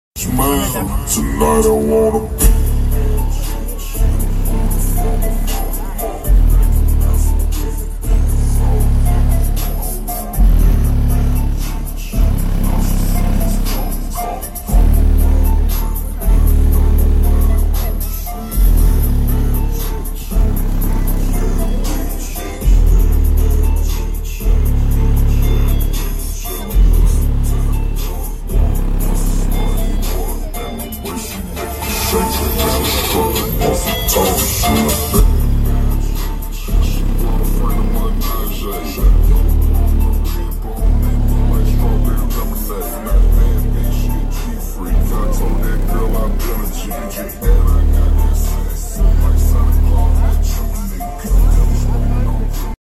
VW Bass Banging, Roof And Sound Effects Free Download